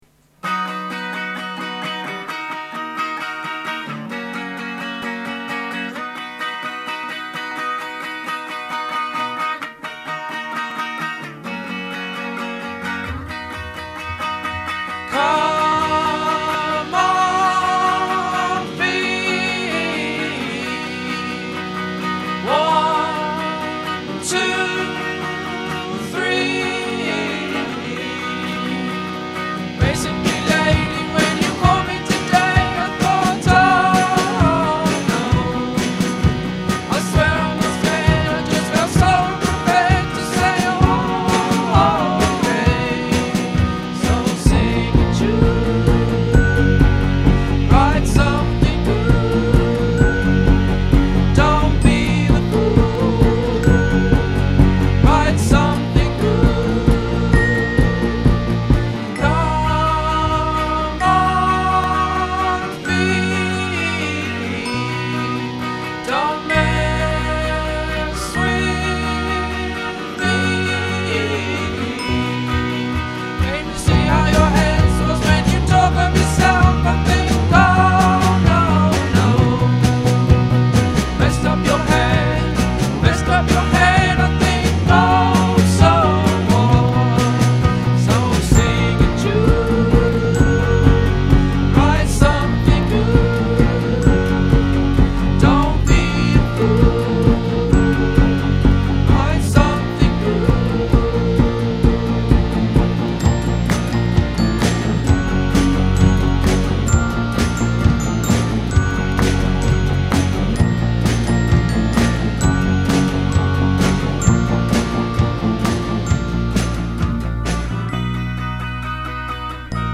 più addolcita e con qualche parola sbagliata
(demo)